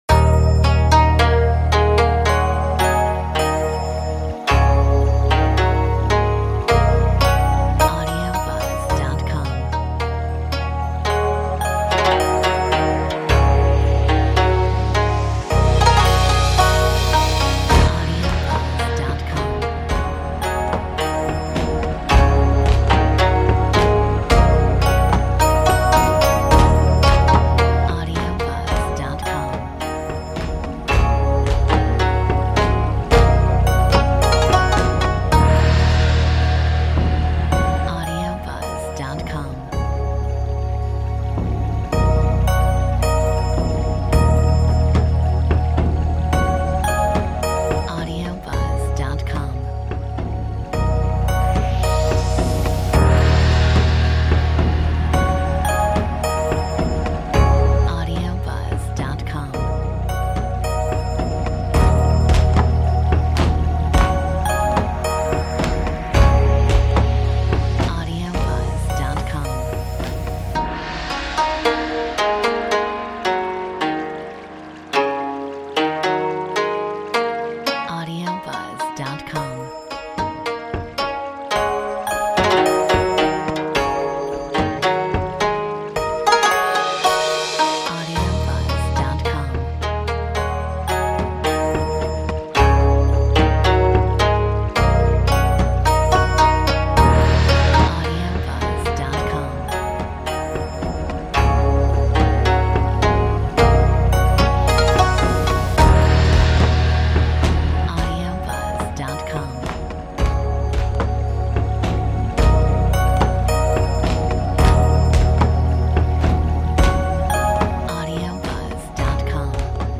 Metronome 109